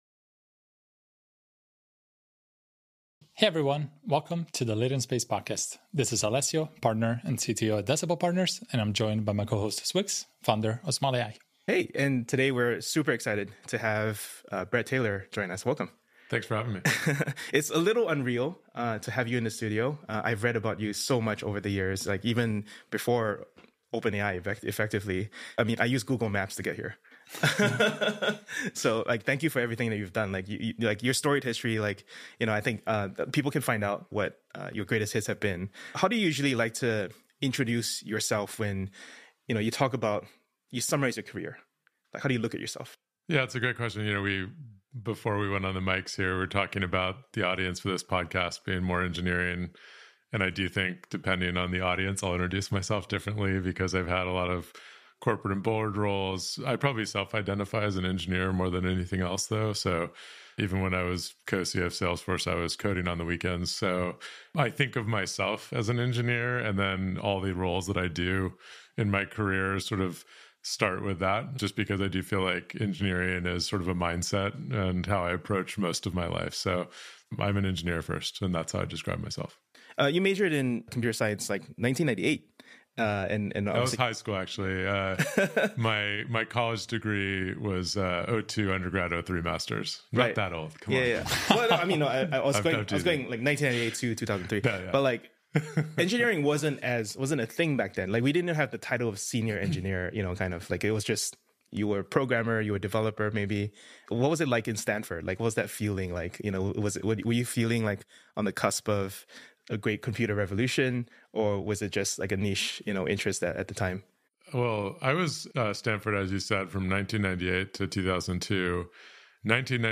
Guest Bret Taylor